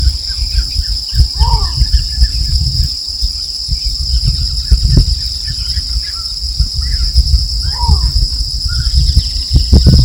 Mirasol Chico (Botaurus exilis)
Nombre en inglés: Least Bittern
Localización detallada: Reserva Natural Don Luis
Condición: Silvestre
Certeza: Vocalización Grabada
Mirasol-chico-OK.mp3